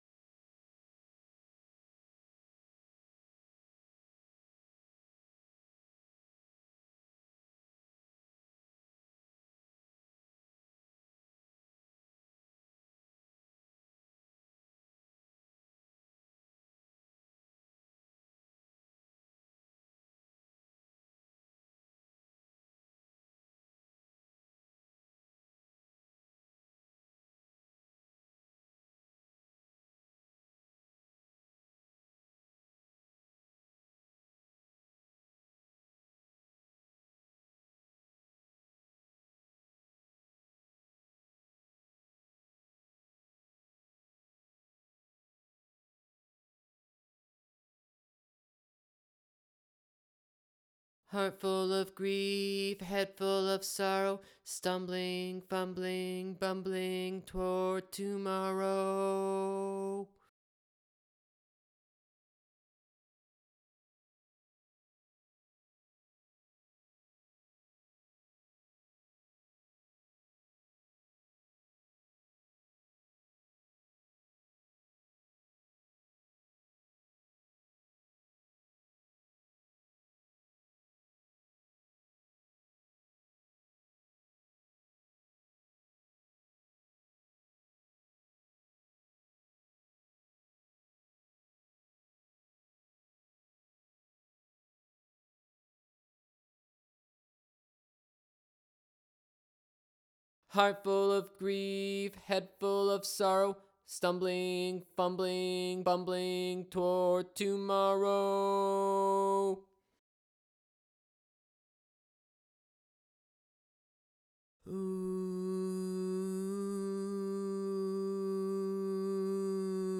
Toward Tomorrow - Vocal Chorus 2 - Mid High.wav